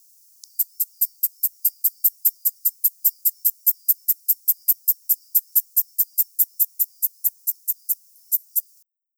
mountaineer shieldback
9 s of calling song and waveform. Kern County, California; 21.0°C. R83-292.